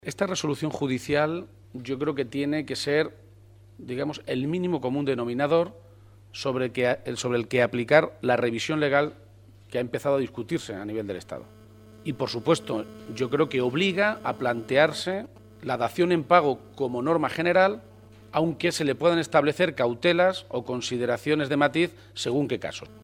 Se ha pronunciado de esta manera en una comparecencia ante los medios de comunicación en Guadalajara, poco antes de mantener una reunión con alcaldes y concejales socialistas de toda la provincia.
Cortes de audio de la rueda de prensa